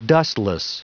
Prononciation du mot dustless en anglais (fichier audio)